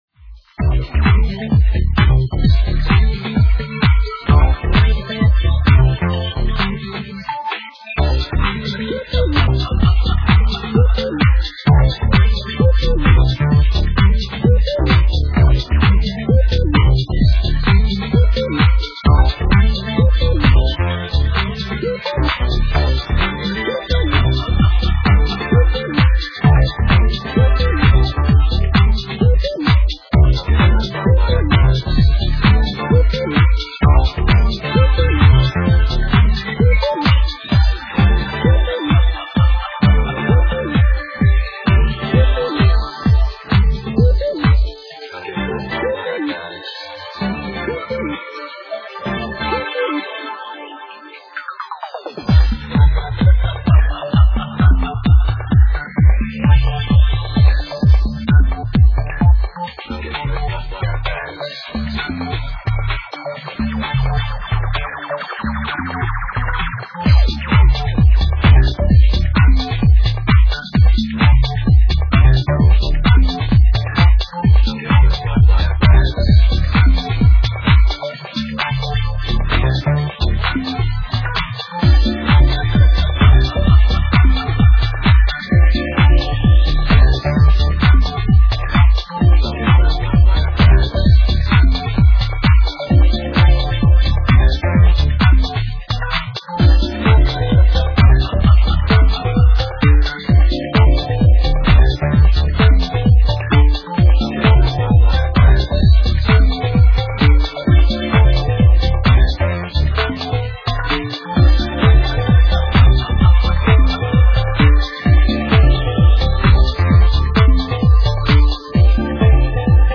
Nostalgia that touches the future.